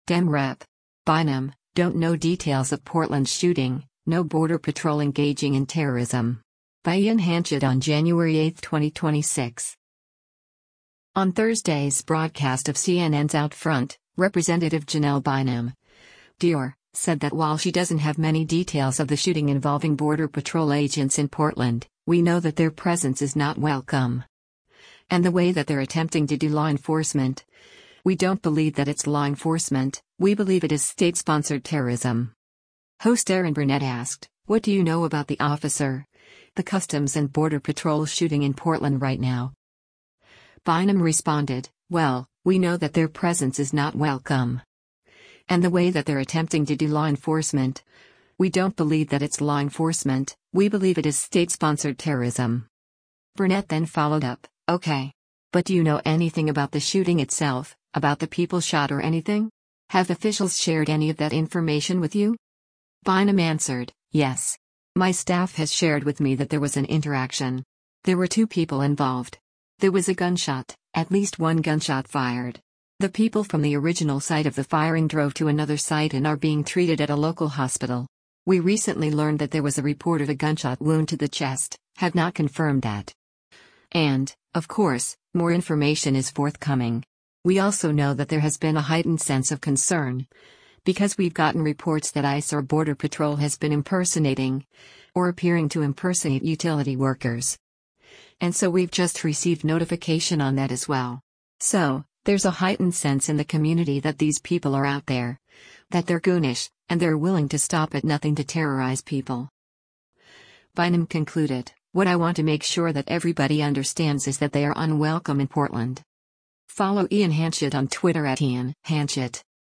Host Erin Burnett asked, “What do you know about the officer — the Customs and Border Patrol shooting in Portland right now?”